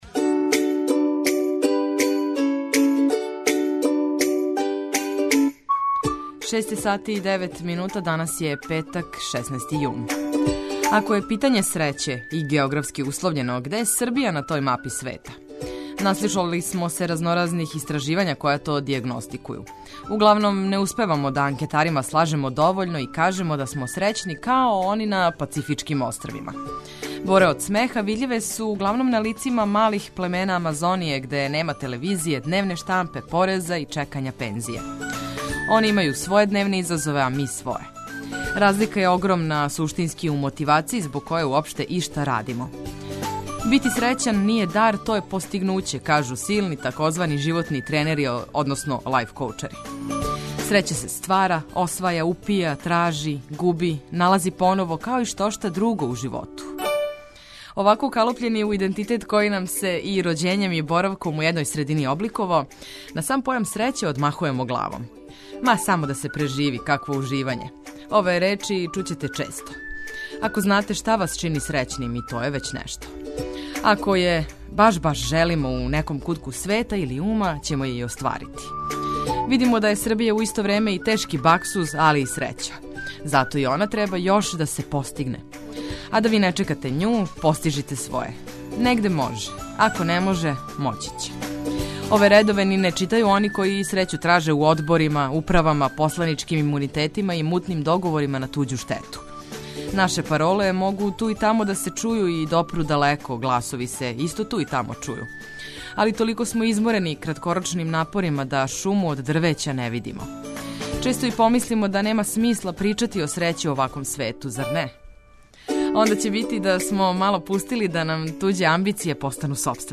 Нови дан дочекујемо уз осмех, много корисних и важних информација, а јутро улепшавамо и музиком уз коју ћете сигурно лакше да се разбудите, певушећи у сусрет свим обавезама.